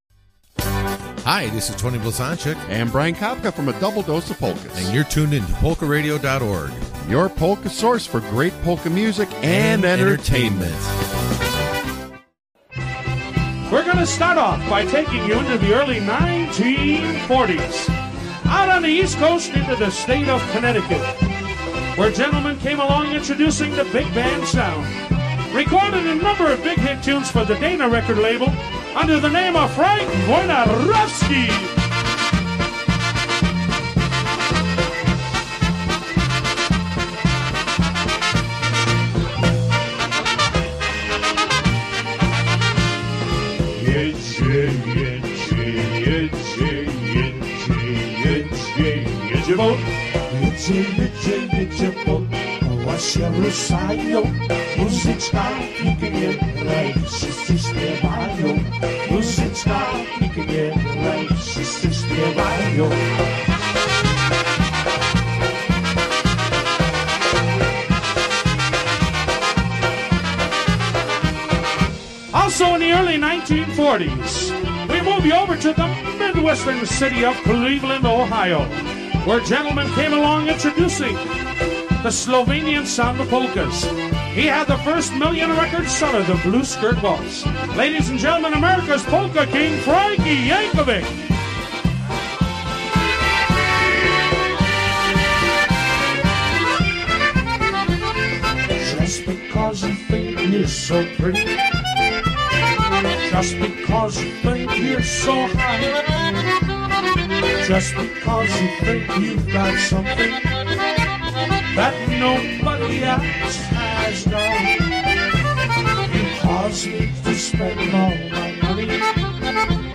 A Polka Show